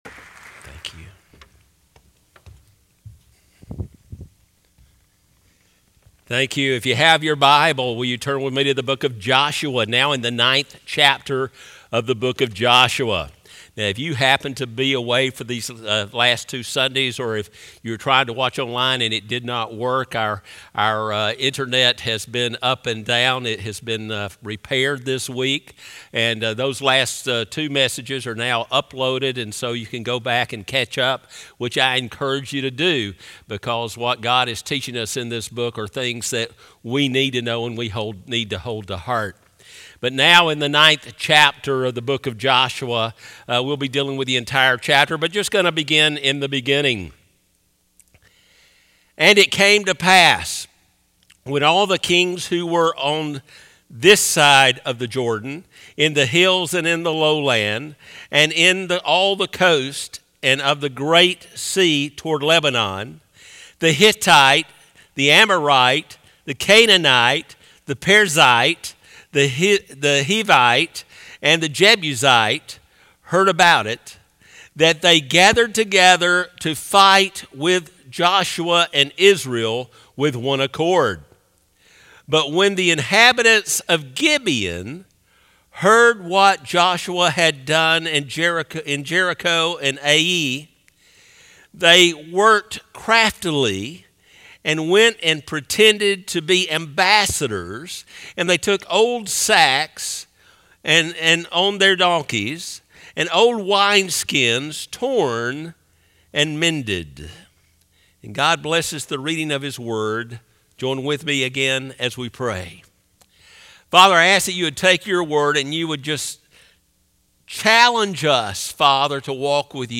Sermons - Northside Baptist Church